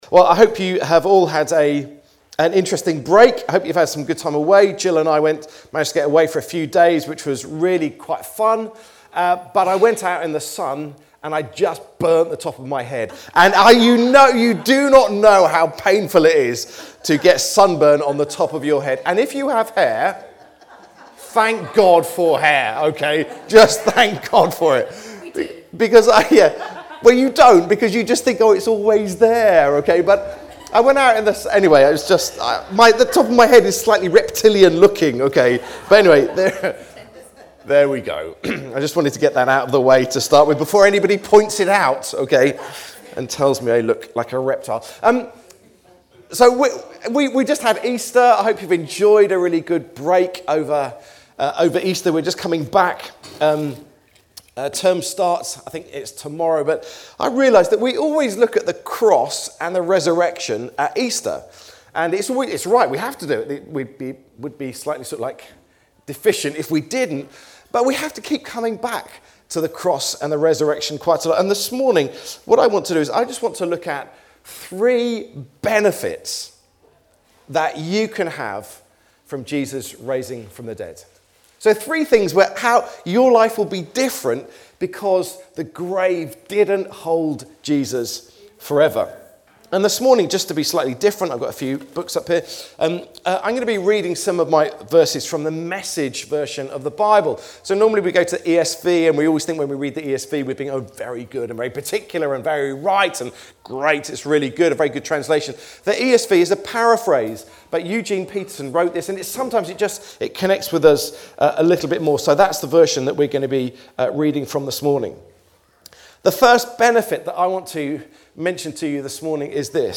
Download Three Benefits that we have from Jesus Raising from the Dead | Sermons at Trinity Church